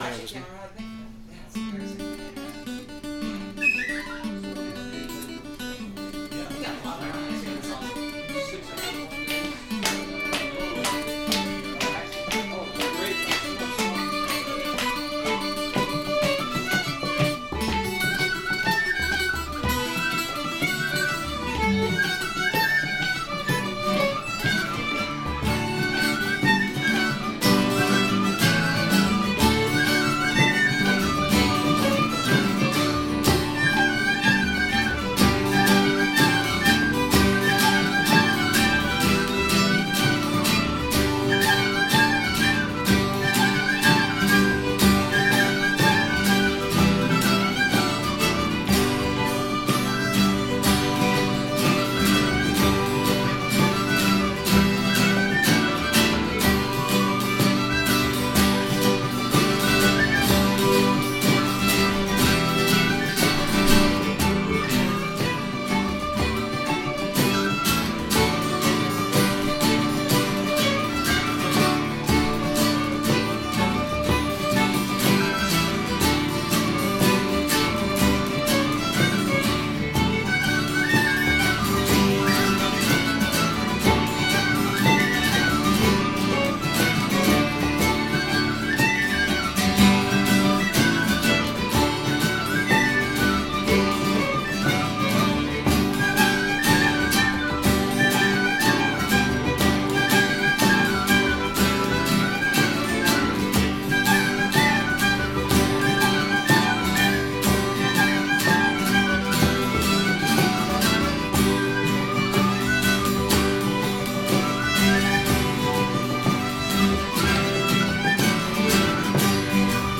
jig_of_slurs.mp3